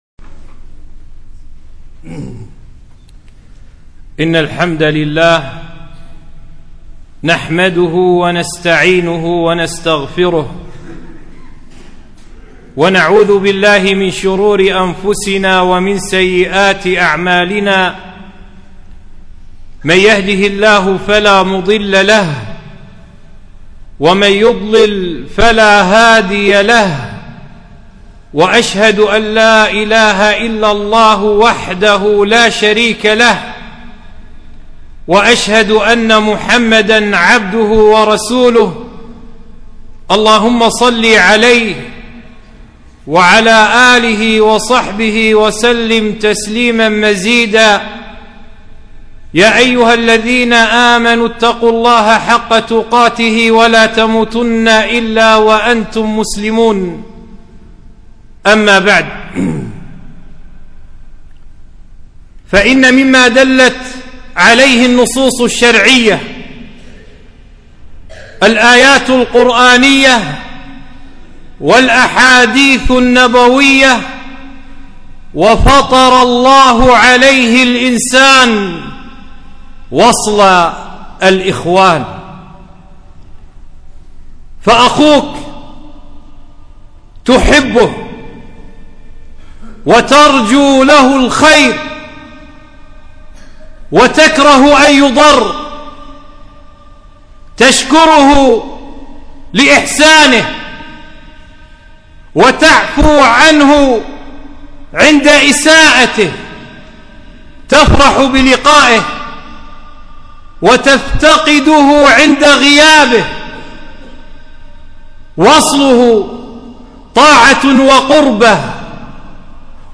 خطبة - صلة الإخوان